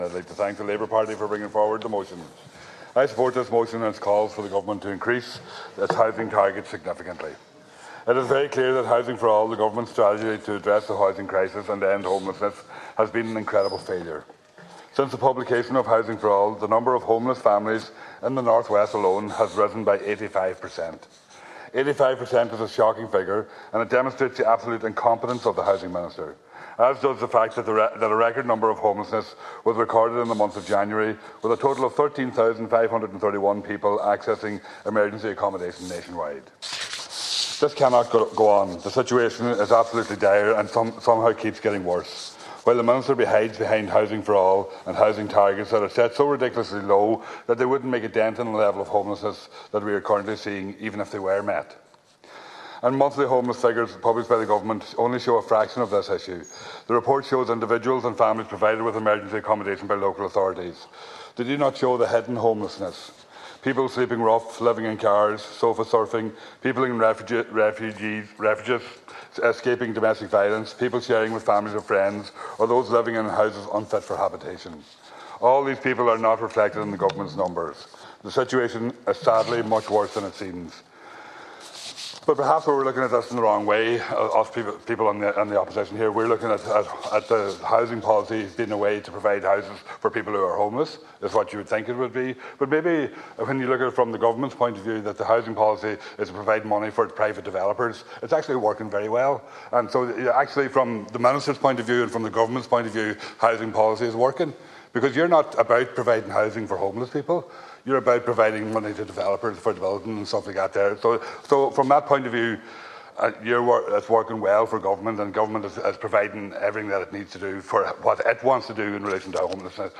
During statements on a Labour Party motion on housing targets and regulation, Donegal Deputy Thomas Pringle says the initiative has been an incredible failure.
He told the Dail that the shortage of housing is not yet realised as the hidden homeless is not taken into account in official figures.